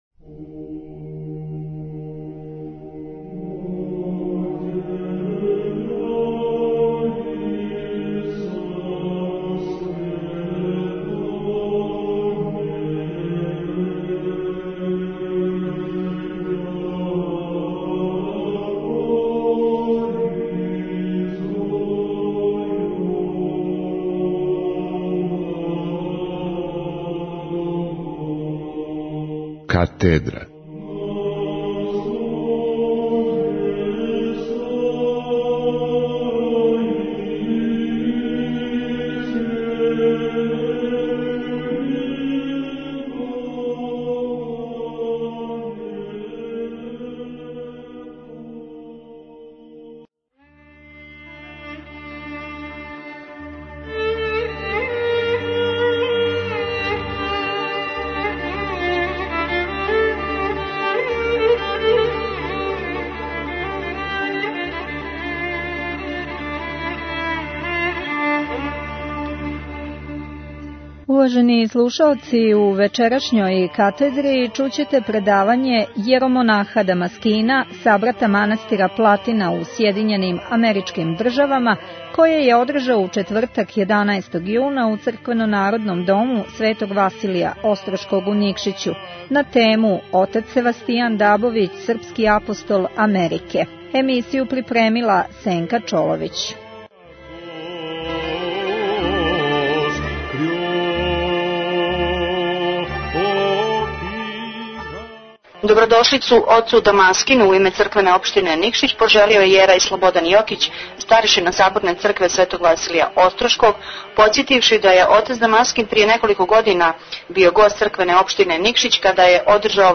Предавање
које је у четвртак 11. јуна одржао у Црквено - народном дому Светог Василија Остроког у Никшићу. Архимандрит Севастијан (Дабовић) је крајем XIX вијека и почетком XX вијека крстарио Америком ширећи јеванђелску поруку, а његови земни остаци сада почивају на српском праволсавном гробљу у Џексону, поред прве српске православне цркве у САД, коју је отац Севастијан подигао 1894. г.